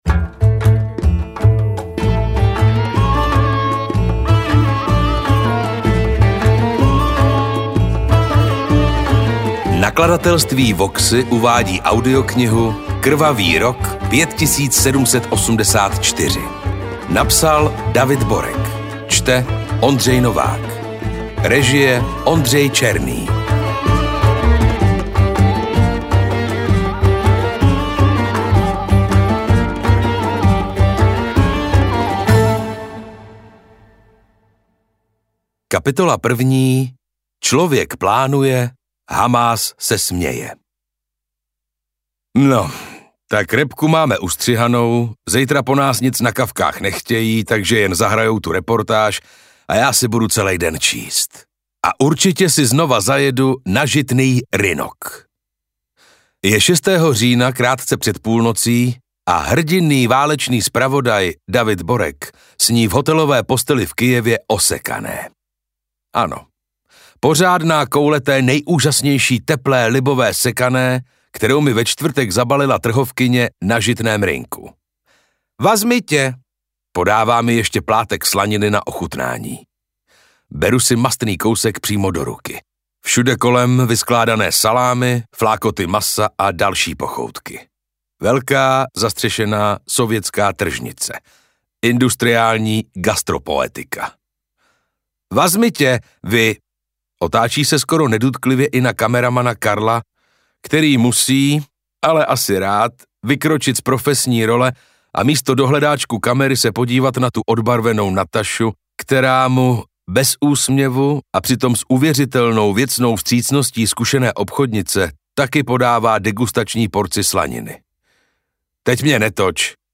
AudioKniha ke stažení, 25 x mp3, délka 9 hod. 47 min., velikost 535,1 MB, česky